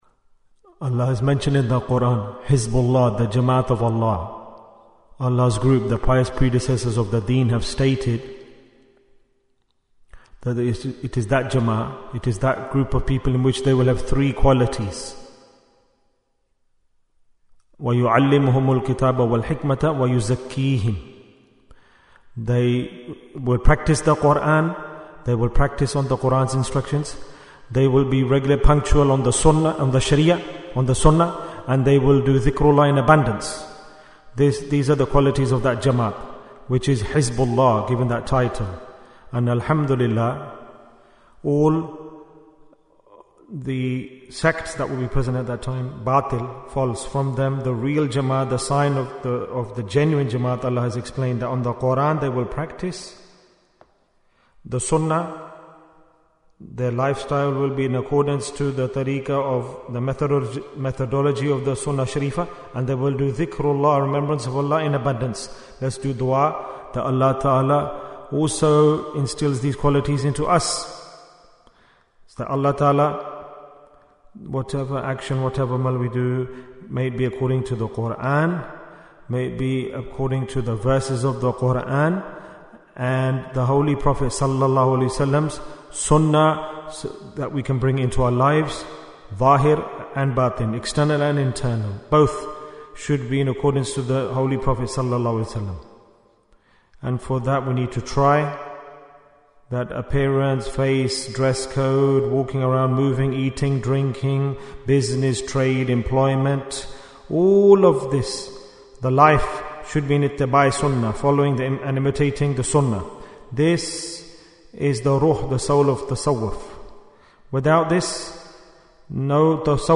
Jewels of Ramadhan 2025 - Episode 20 - The Importance of Sunnah Bayan, 14 minutes17th March, 2025